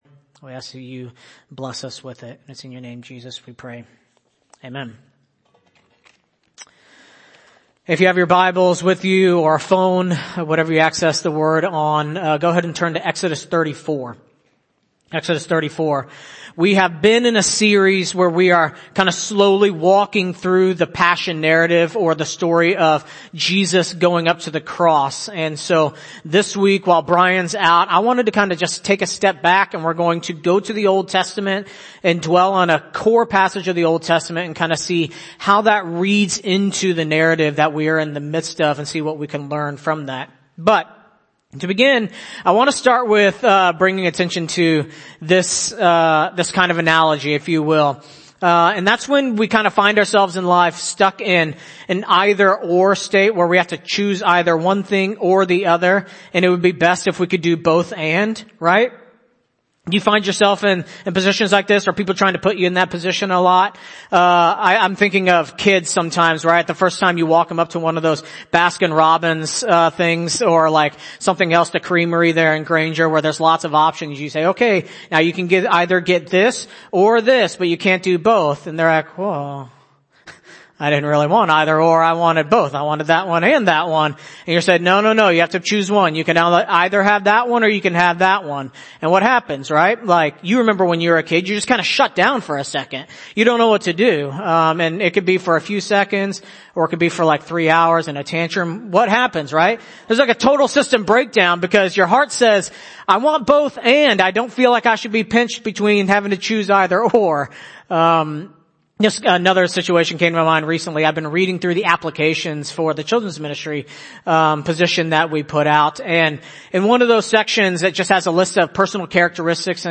2022 ( Sunday Morning ) Bible Text